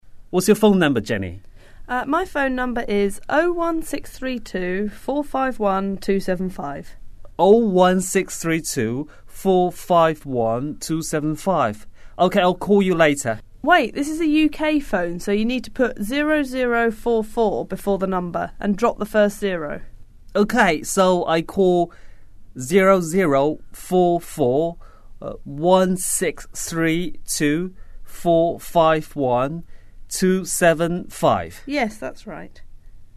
英语初学者口语对话第81集：你的电话号码是多少